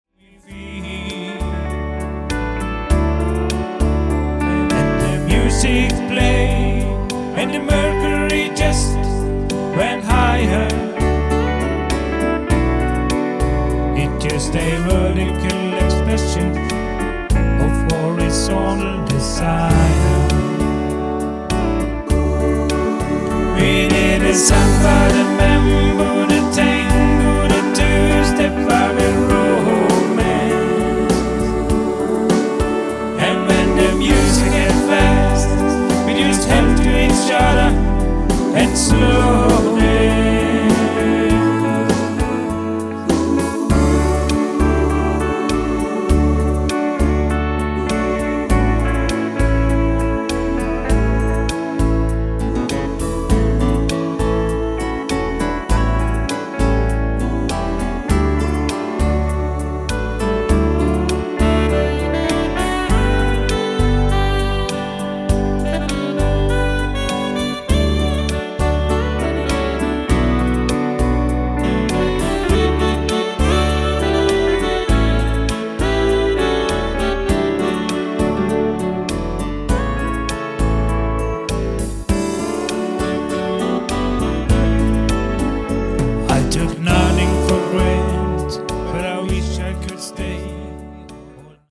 Enmannsorkester